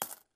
coin_coin_6.ogg